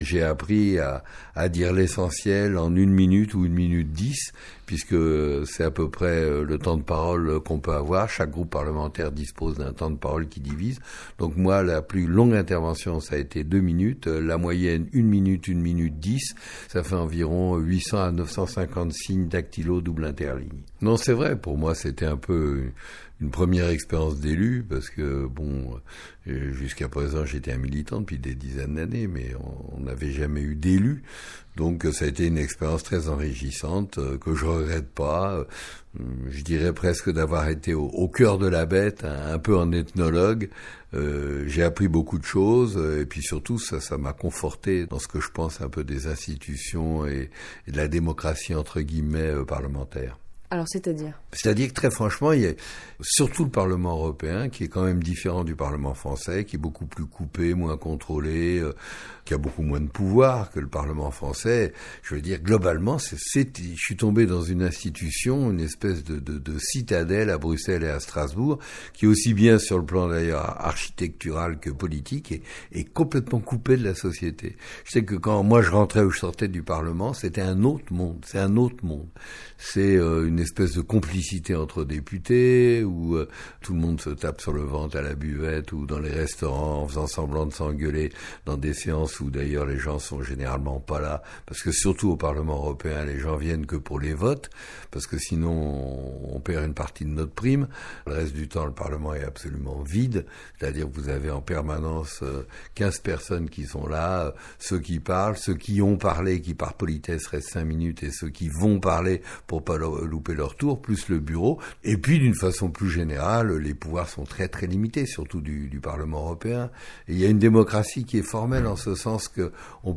Dans ce court entretien, Alain Krivine revient sur son travail de député au parlement européen de Bruxelles et pointe de nombreuses carences entre le discours officiel (objectifs démocratiques, droits du citoyen etc.) et la triste réalité institutionnelle.